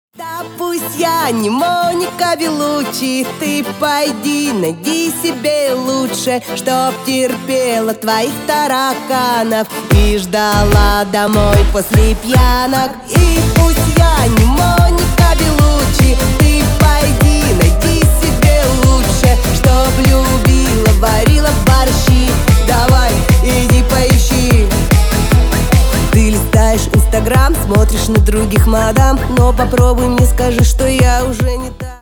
Танцевальные
клубные # весёлые